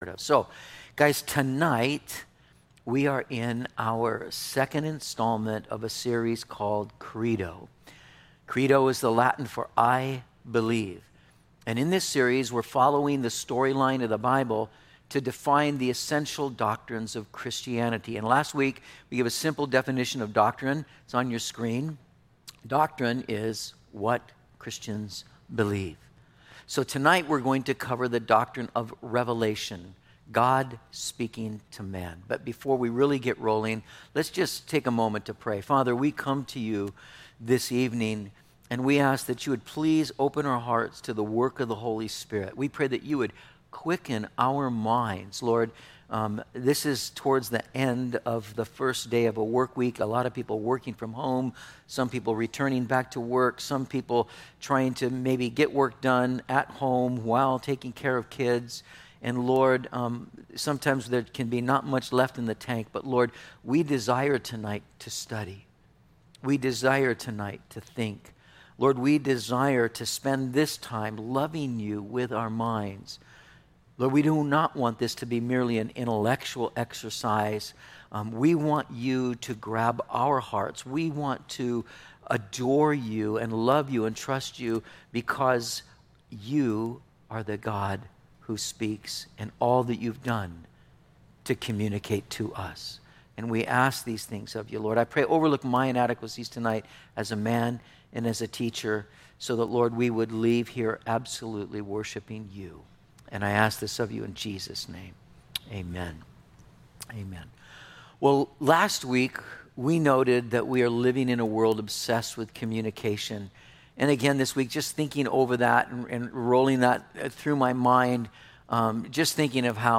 06/22/20 Revelation - Metro Calvary Sermons